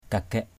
/ka-ɡ͡ɣɛʔ/ (d. đg.) cưa = scier, scie.. mâk kagaik nao kagaik kayau mK k=gK _n< k=gK ky~@ lấy cưa đi cưa gỗ.